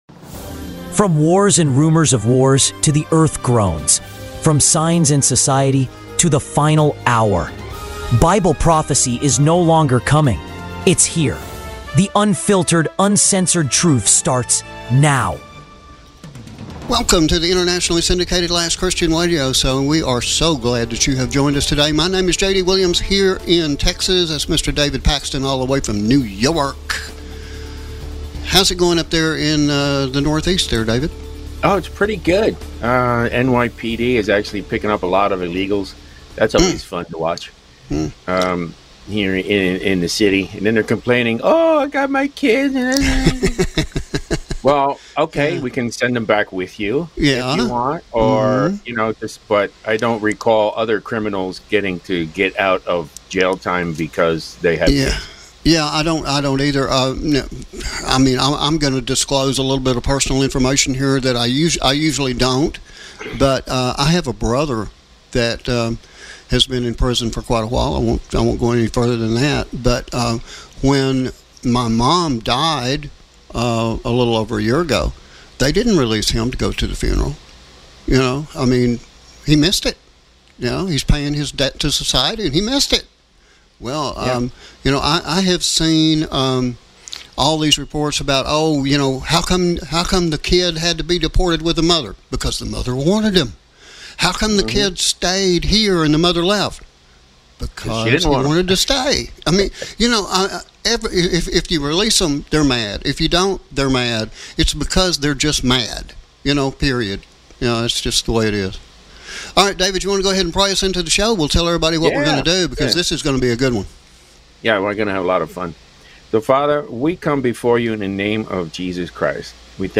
🌍 WORLD EVENTS THROUGH A BIBLICAL LENS | The Last Christian Radio Show 🎙